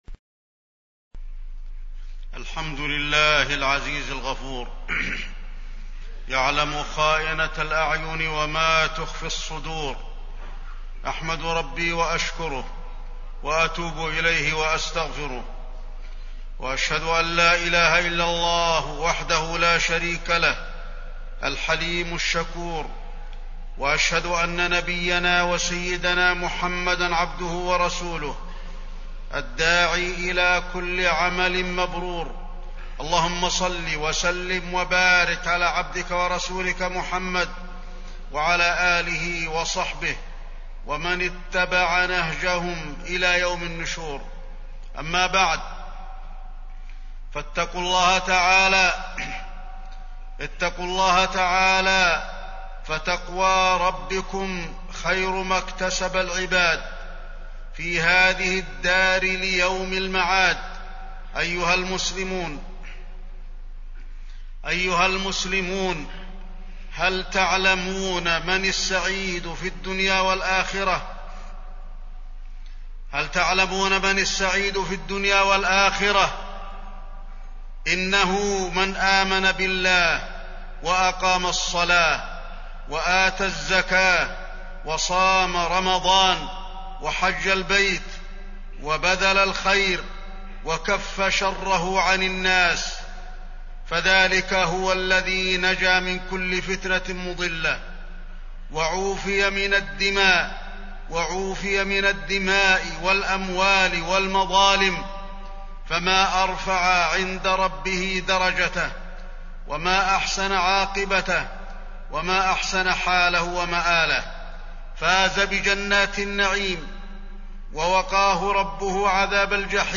تاريخ النشر ٤ جمادى الآخرة ١٤٢٧ هـ المكان: المسجد النبوي الشيخ: فضيلة الشيخ د. علي بن عبدالرحمن الحذيفي فضيلة الشيخ د. علي بن عبدالرحمن الحذيفي فتنة الخوارج